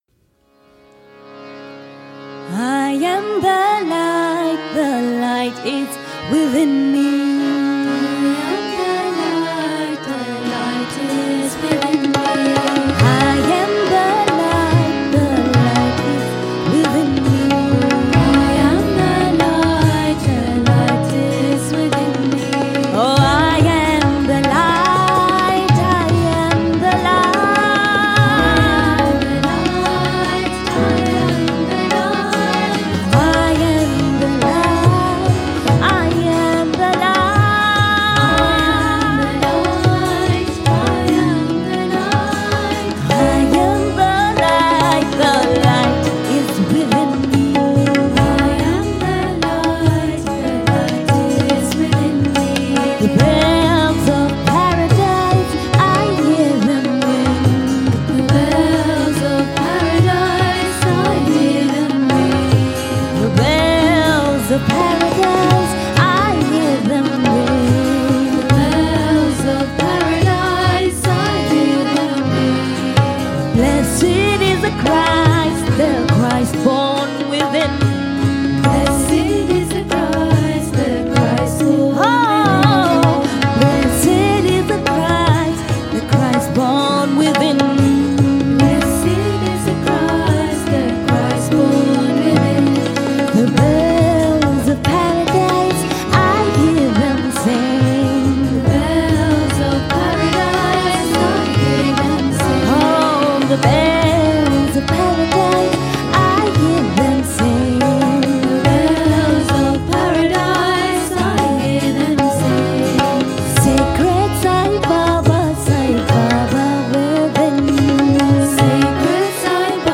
1. Devotional Songs
Minor (~ Natabhairavi)
6 Beat / Dadra
Medium Slow